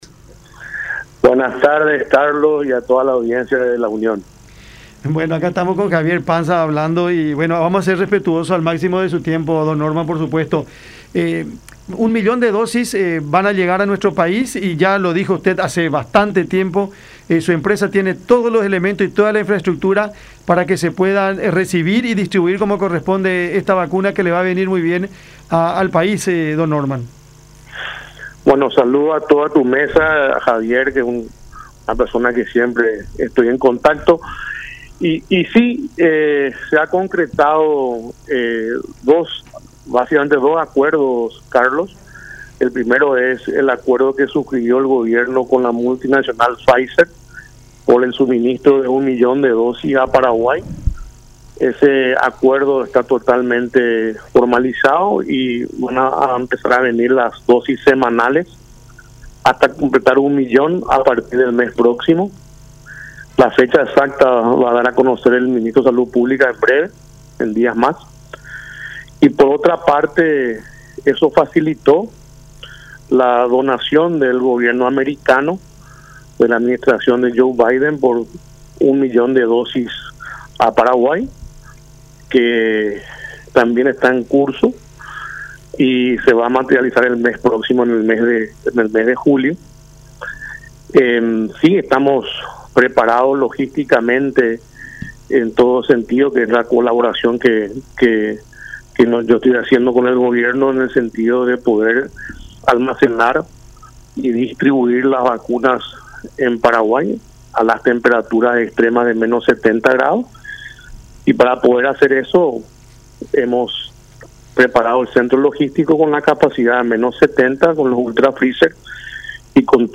en charla con Cada Siesta por La Unión